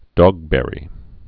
(dôgbĕrē, dŏg-)